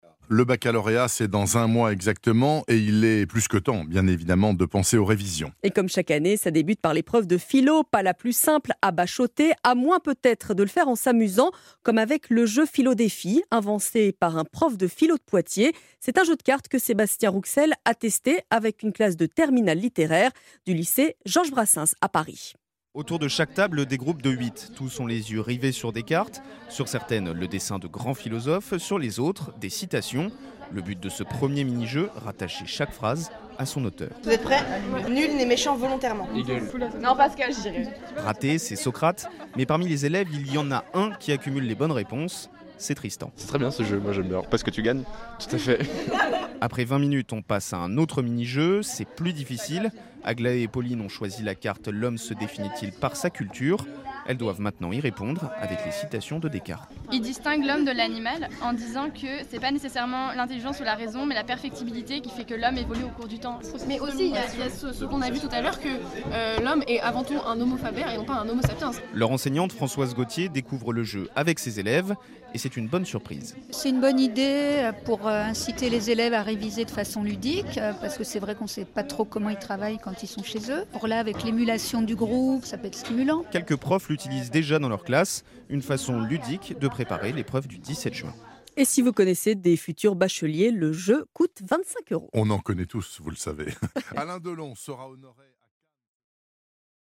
reportage
diffusé dans la matinale de Bernard Poirette ce dimanche 19 mai !